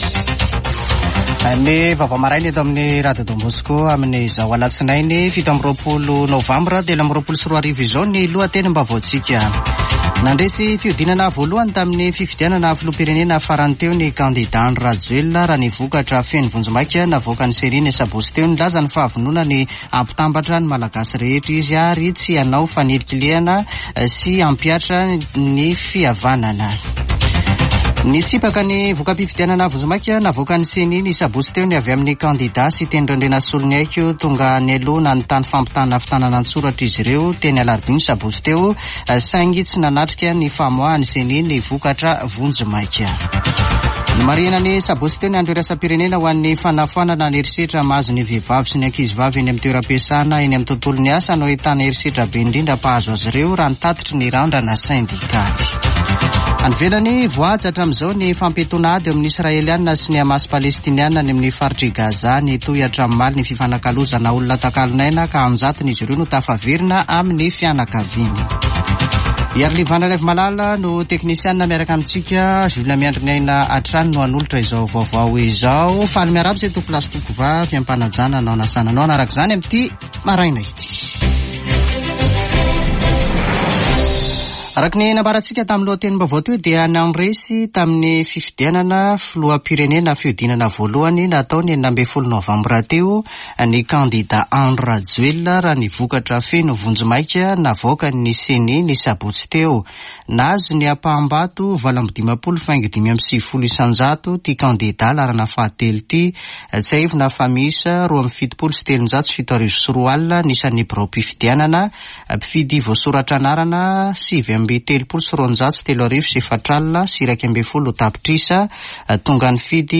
[Vaovao maraina] Alatsinainy 27 nôvambra 2023